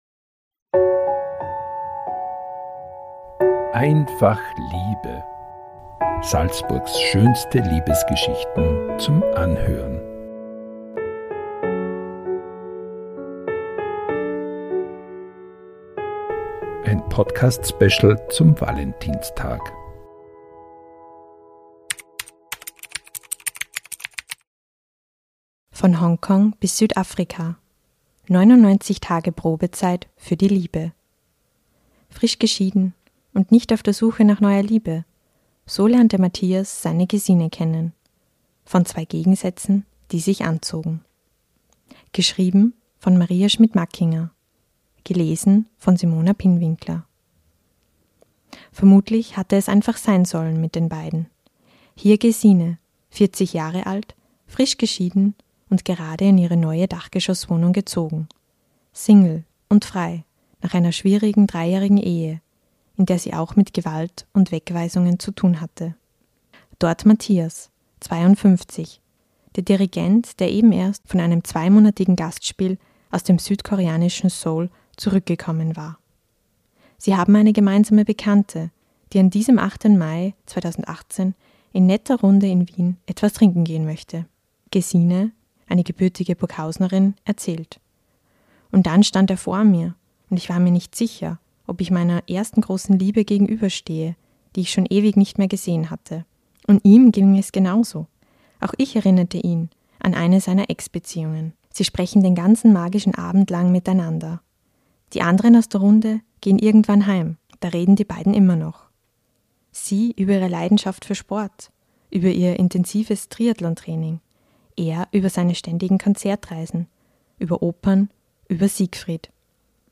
Pünktlich zum Valentinstag lesen SN-Redakteurinnen und Redakteure Salzburgs schönste Liebesgeschichten ein.